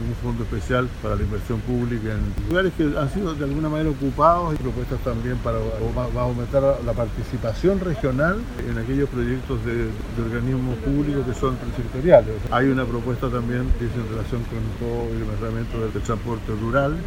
También estuvo presente en la cita el senador socialista, José Miguel Insulza, quien representa a Arica y Parinacota.